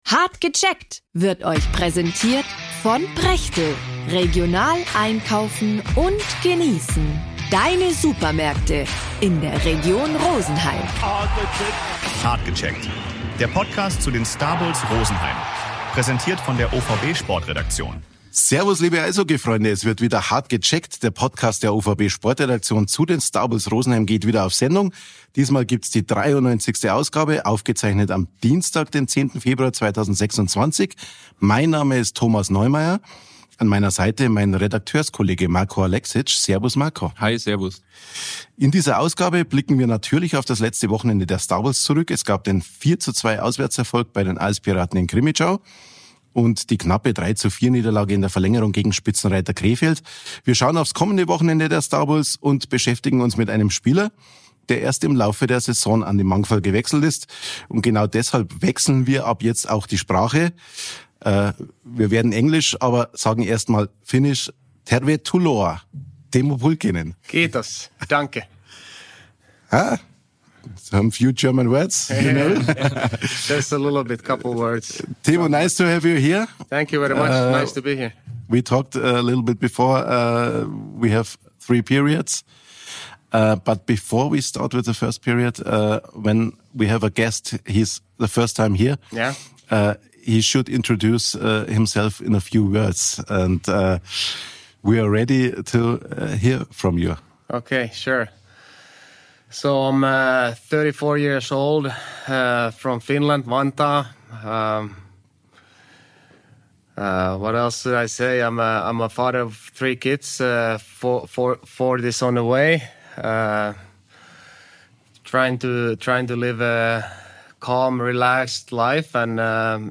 Teemu Pulkkinen ist der Gast der OVB-Sportredaktion in der 93. Folge von „Hart gecheckt“, dem OVB-Podcast rund um die Starbulls Rosenheim.